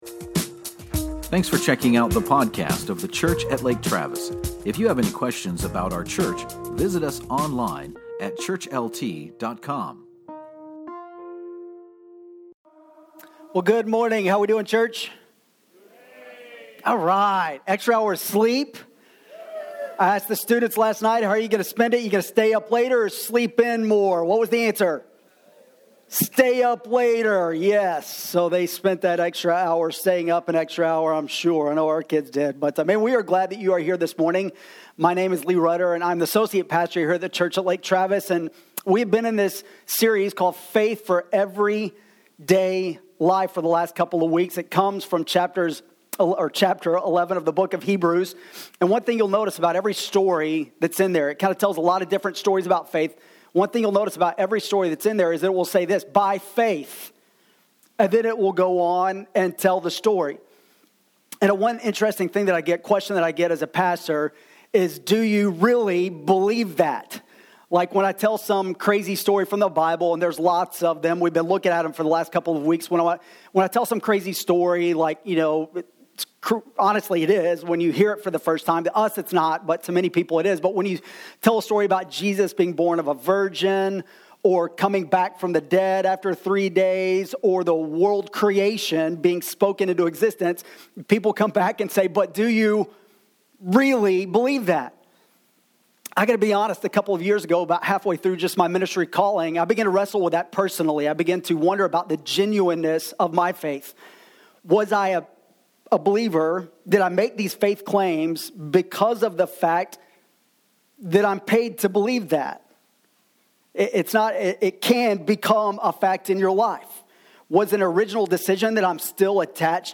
Do you believe the best in kids because of what they have or because of what God has for them? This and more in this message from Hebrews 11 on the faith of Moses.